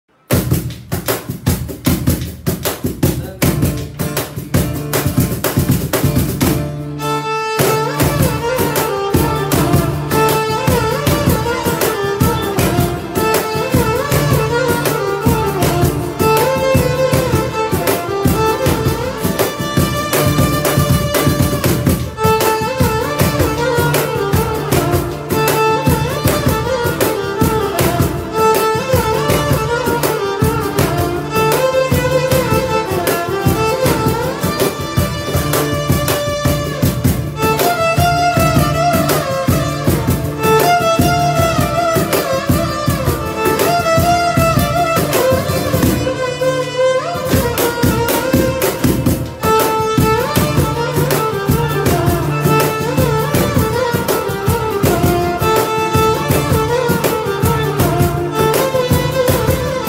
Tamil Ringtones